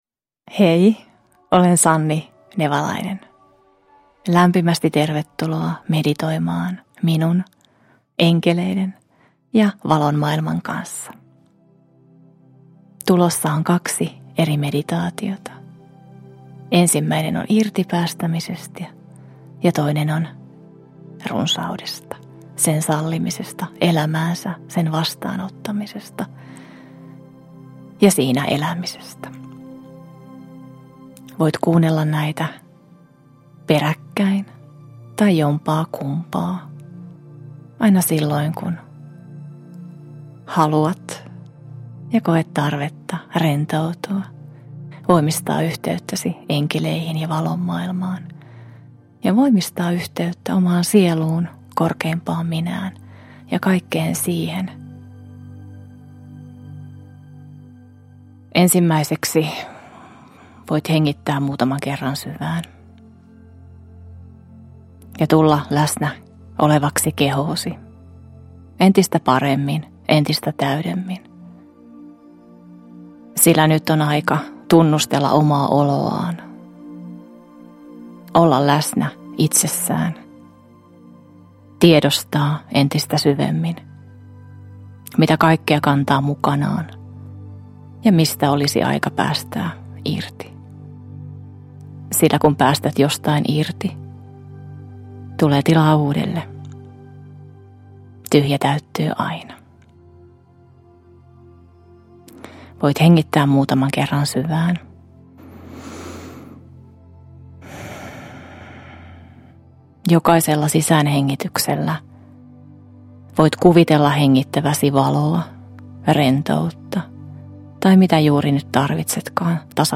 Tie runsauteen -meditaatio – Ljudbok – Laddas ner
Äänitteessä on kaksi noin puolen tunnin pituista meditaatiota.